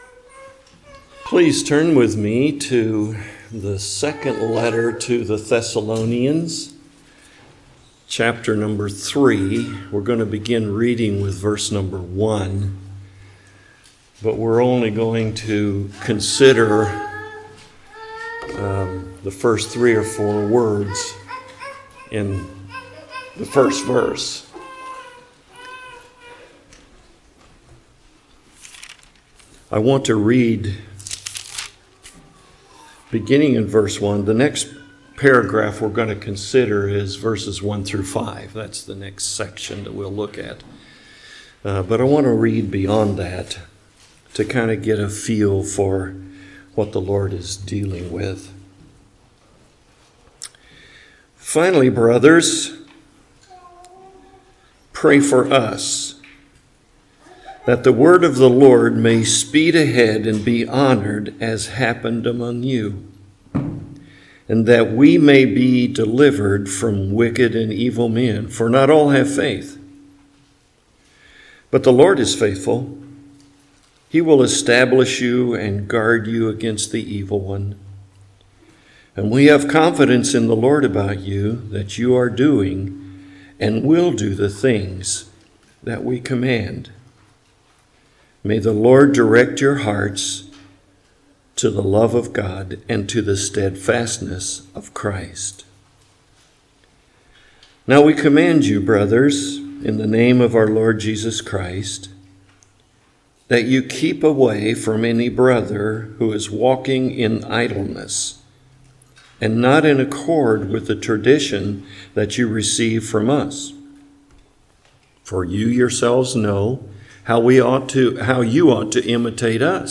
2 Thessalonians Passage: 2 Thessalonians 3:1 Service Type: Morning Worship Topics